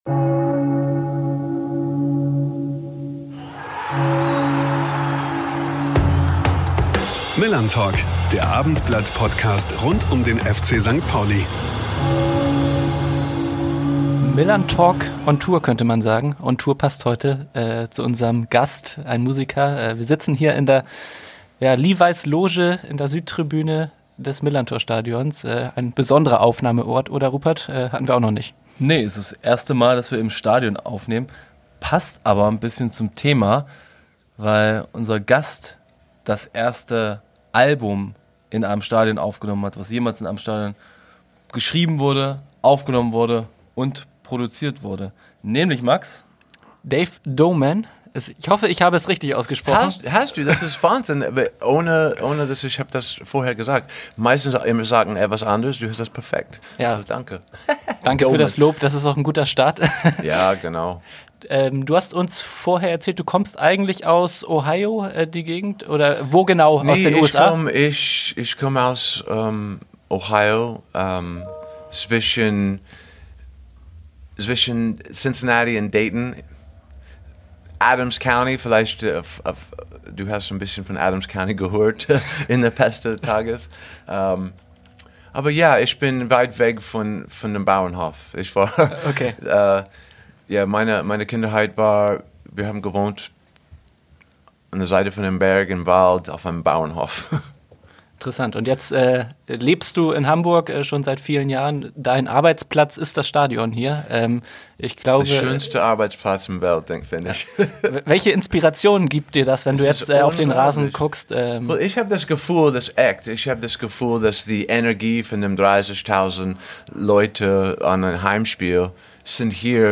Ein Gespräch über Fankultur, Fußball und Musik.